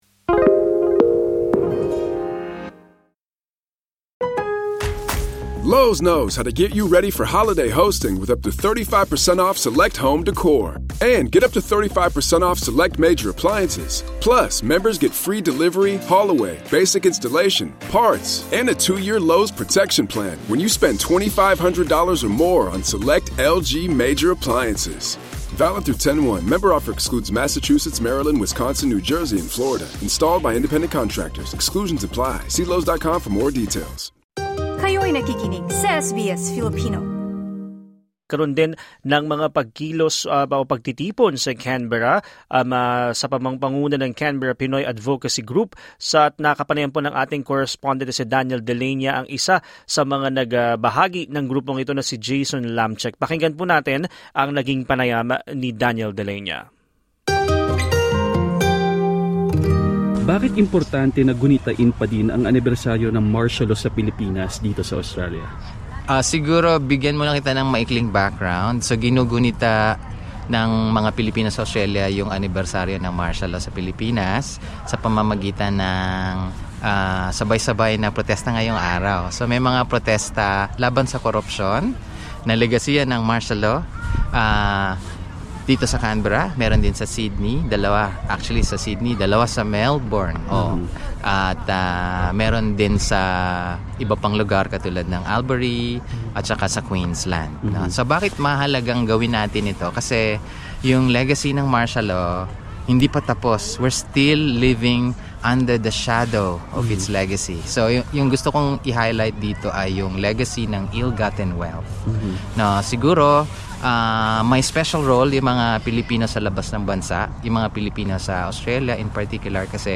Sa panayam ng SBS Filipino